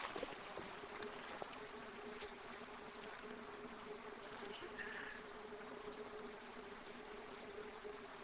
Flüstern
Diese Aufnahme entstand 2007 in der Ruinenstadt Döllersheim. Es herrschte während der Aufnahme eine absolute Stille.